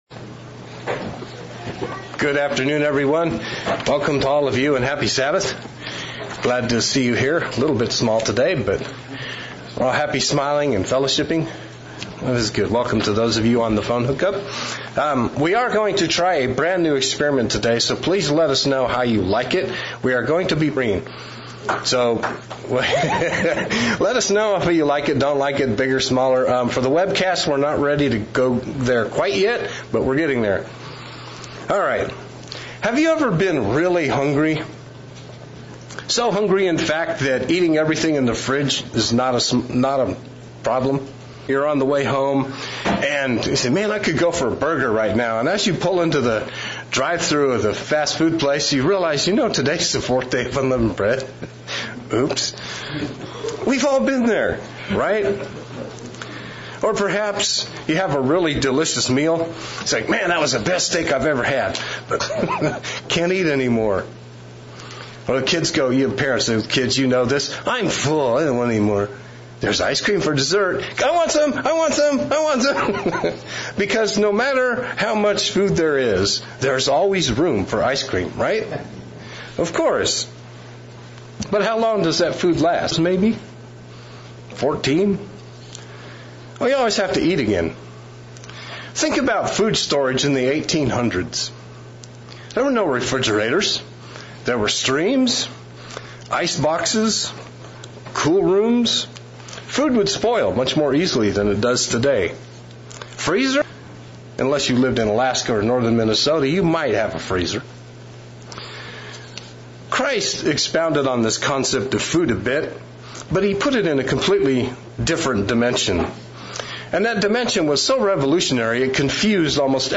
Sermonette looking at physical versus spiritual food and how can we partake of the food that leads to eternal life.